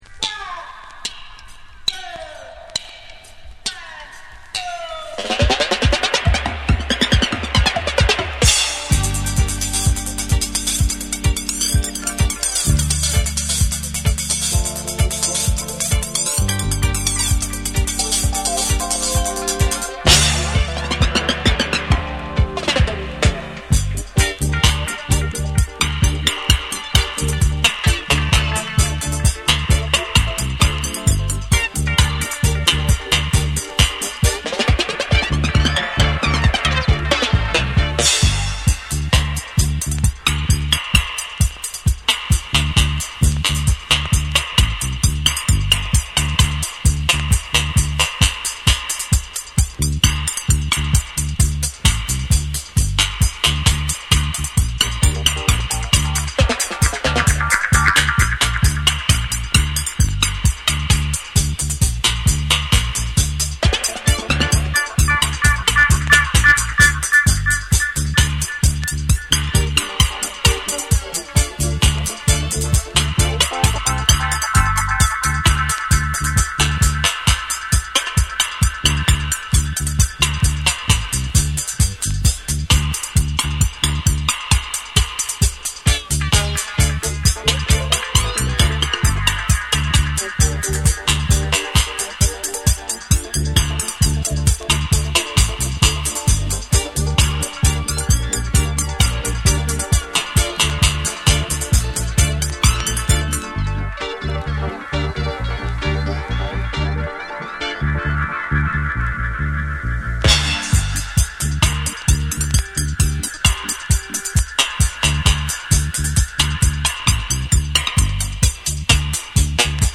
ヘヴィなステッパーズ・リズムを軸に、深く沈み込むベースとエコー／リバーブを効かせた空間的なミックスが際立つダブを収録。
※チリノイズ入る箇所あり。
REGGAE & DUB